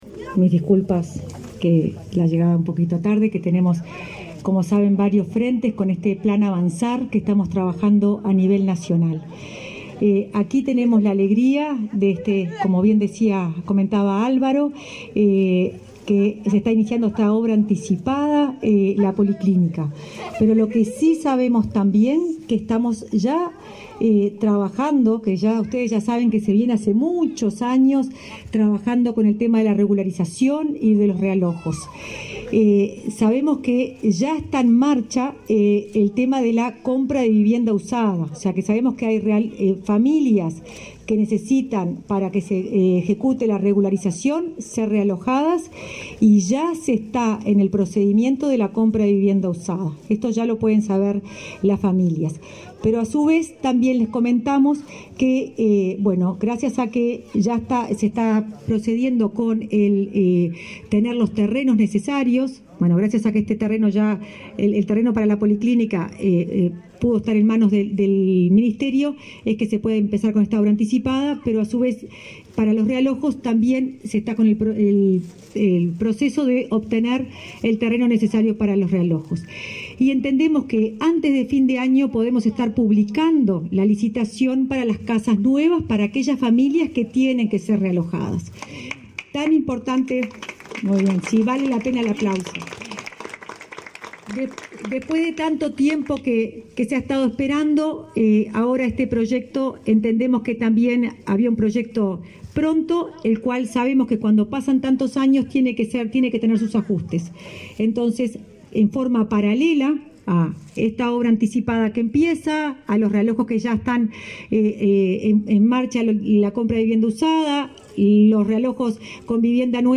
El ministro de Vivienda y Ordenamiento Territorial, Raúl Lozano, participó, este 14 de setiembre, en el acto en que se anunció el inicio de obras, por el Programa de Mejoramiento de Barrios (PMB), en Nuevo Amanecer y Los Reyes, de Montevideo. En la oportunidad, también disertó la directora de Integración Social y Urbana, Florencia Arbeleche.